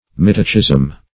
mytacism - definition of mytacism - synonyms, pronunciation, spelling from Free Dictionary
Search Result for " mytacism" : The Collaborative International Dictionary of English v.0.48: Mytacism \My"ta*cism\, n. [Gr.